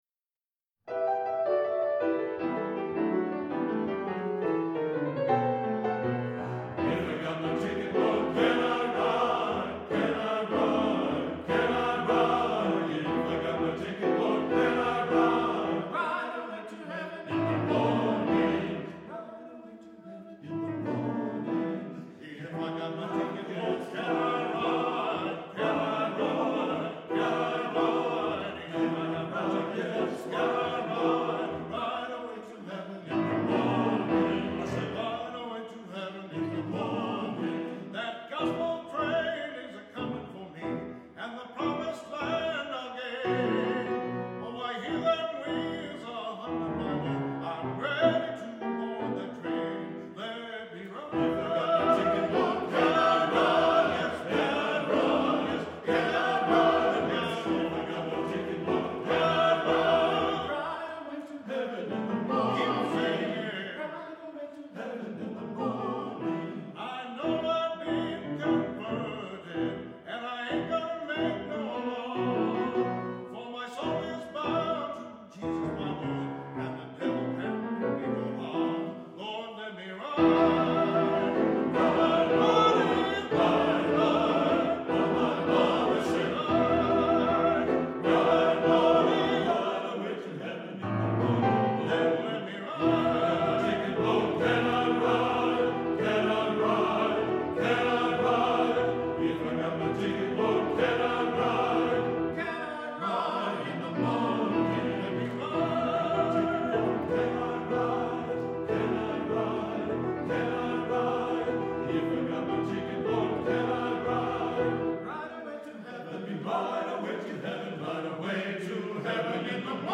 Voicing: SATB and Solo